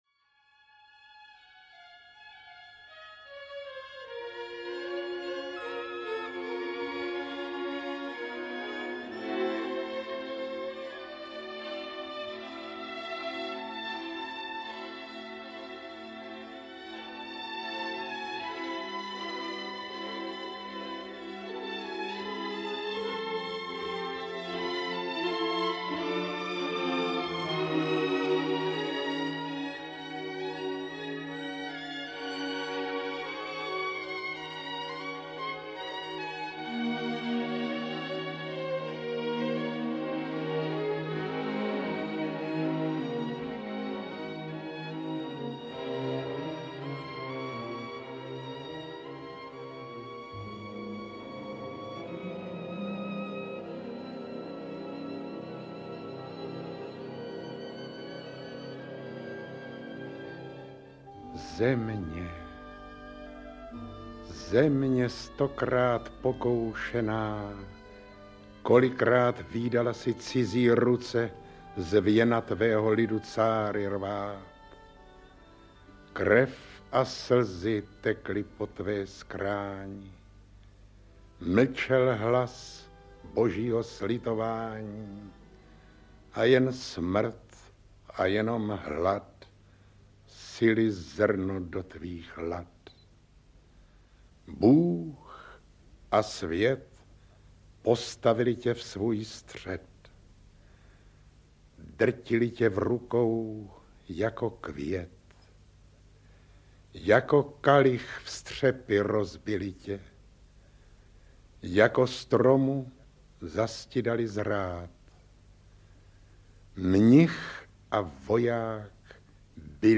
Padesát let republiky. Pásmo veršů k 50. výročí vzniku československého státu audiokniha
Ukázka z knihy
• InterpretOtakar Brousek st., Miroslav Doležal, Ladislav Chudík, Otomar Krejča, Věra Kubánková, Vladimír Ráž, Jaromír Spal, Vladimír Šmeral, Jiří Šrámek, Zdeněk Štěpánek, Marie Tomášová